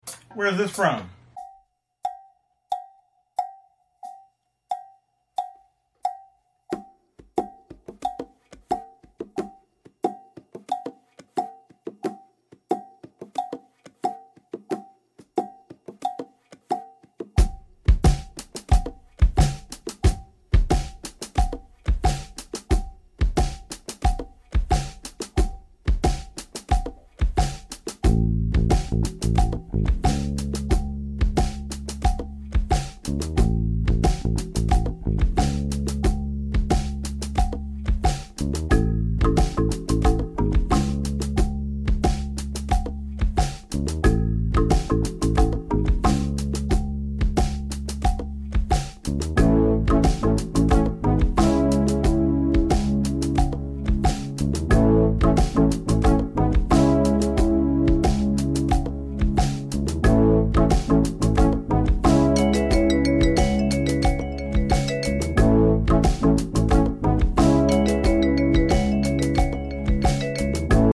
#80smusic